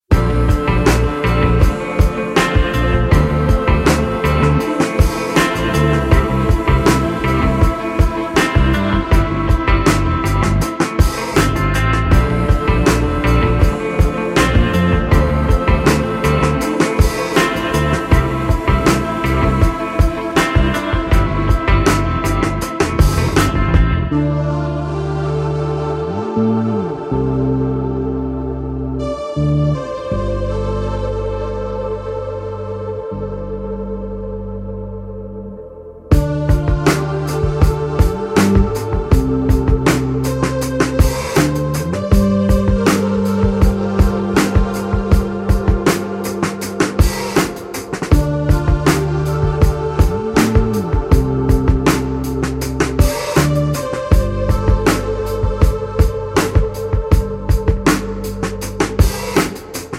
Cinematic Vintage Synth and live drums
Soundtrack Funk Library